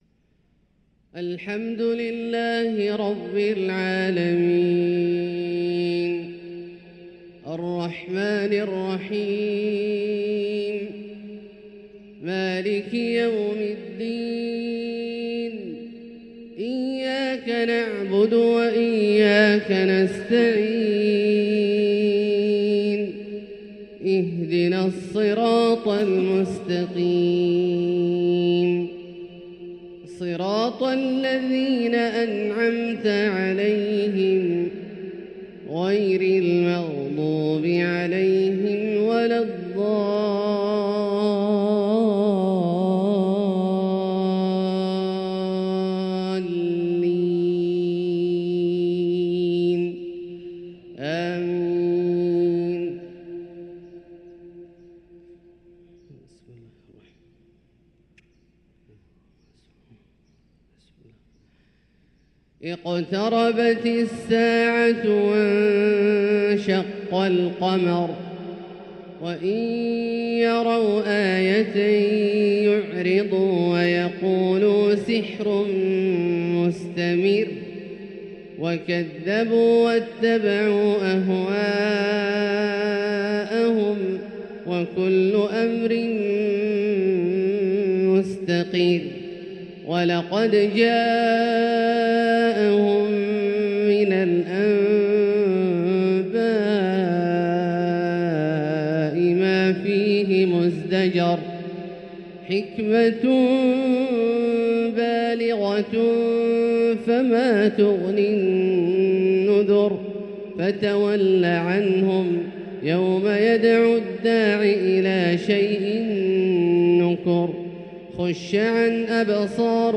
سورة القمر بترتيل بديع | فجر السبت 1-3-1445هـ > ١٤٤٥هـ > الفروض - تلاوات عبدالله الجهني